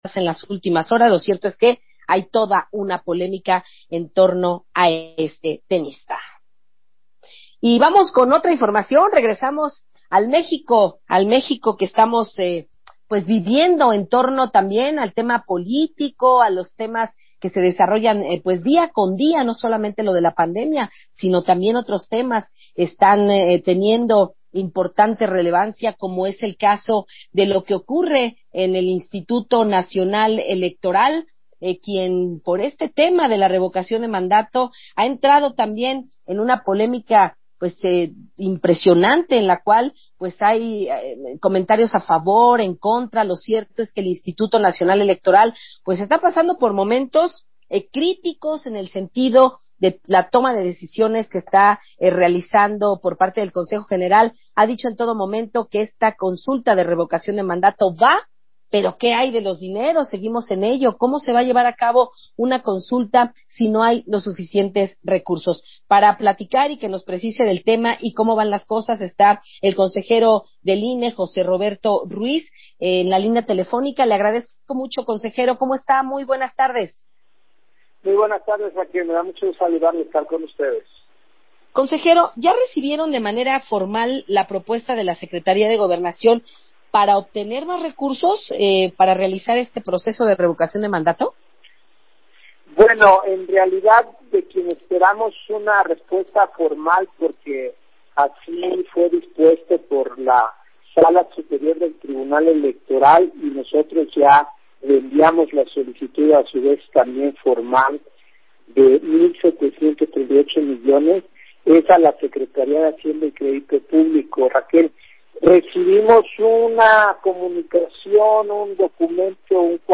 Entrevista-Jose-Roberto-Ruiz
INE espera respuesta formal de la SHCP por solicitud de ampliación presupuestal para Revocación de Mandato: José Roberto Ruiz en entrevista con Radio Fórmula